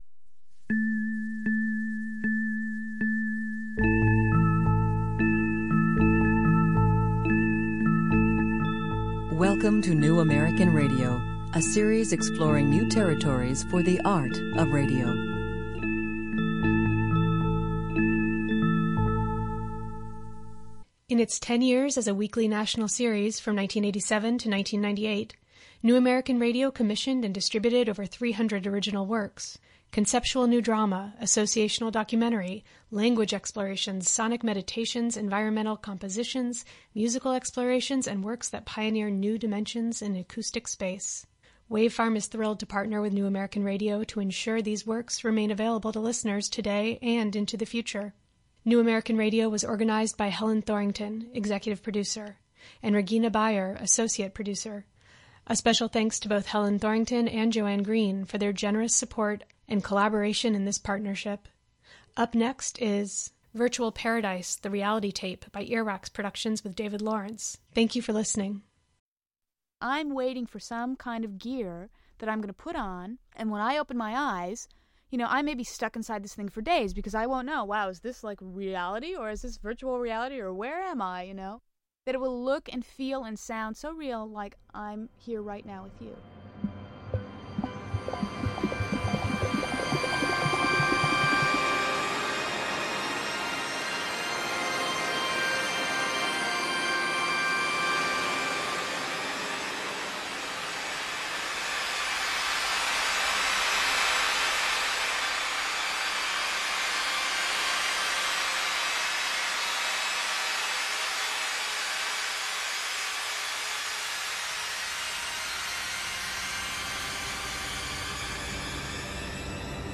Virtual Paradise features many voices recorded at Cyberthon, a 24-hour virtual reality event presented by Whole Earth Institute in 1990. It also includes interviews with such visionaries as science-fiction author William Gibson, VR architect Jaron Lanier, artificial reality pioneer Myron Krueger, and Timothy Leary—all intercut with music and sound effects and shaped into a highly entertaining and insightful "virtual" tape composition.